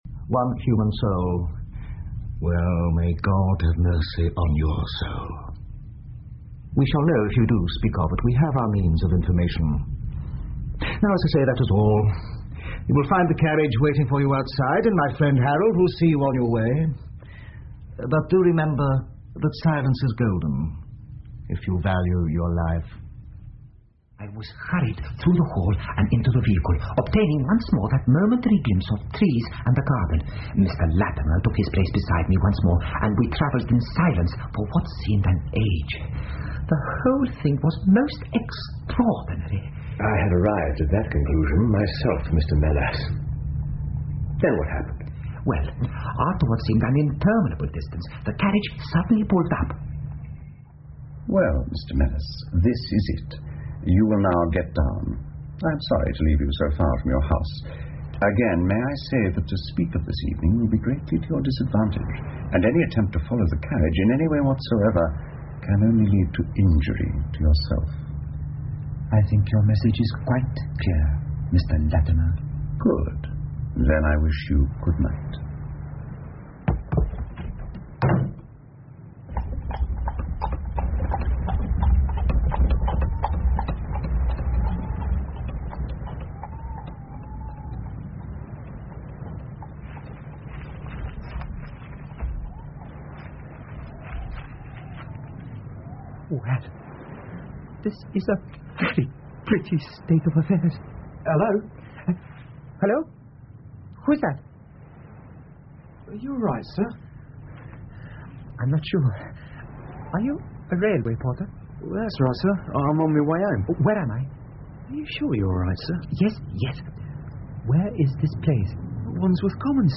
福尔摩斯广播剧 The Greek Interpreter 5 听力文件下载—在线英语听力室